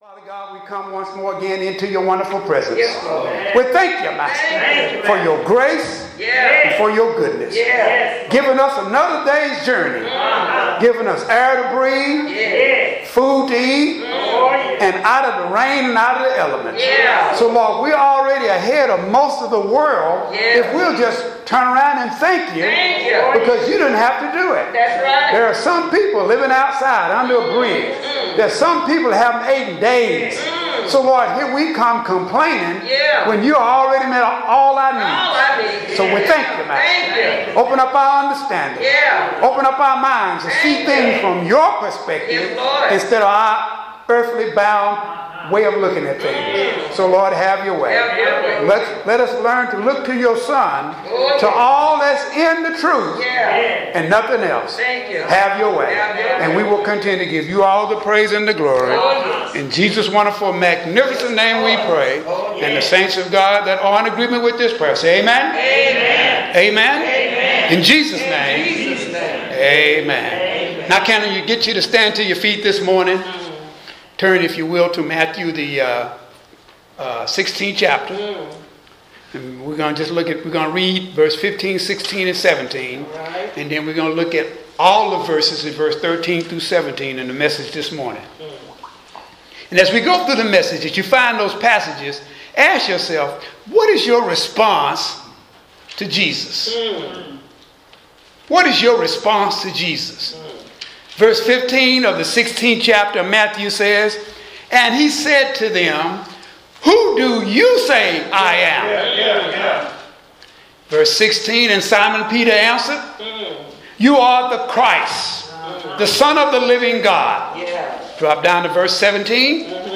Sermon Scripture Matthew 16:15-17 15 “But what about you?” he asked.